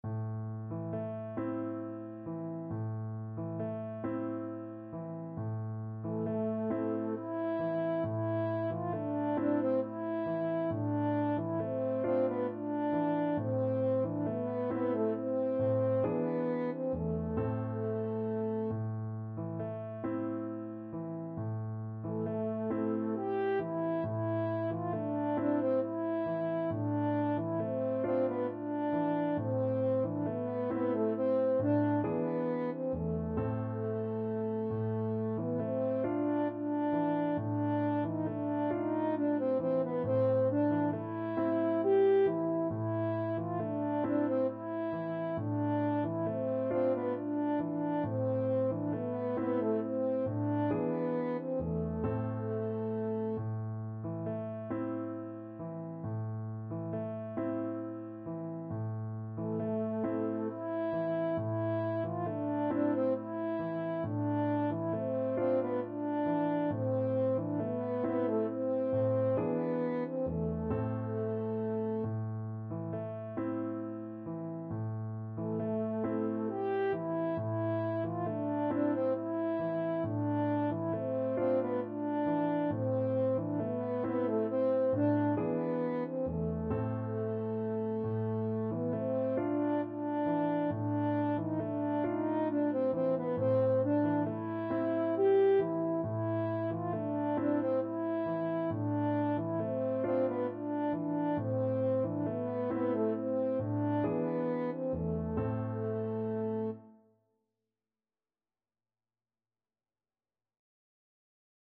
French Horn
Traditional Music of unknown author.
A minor (Sounding Pitch) E minor (French Horn in F) (View more A minor Music for French Horn )
Gently rocking .=c.45
Turkish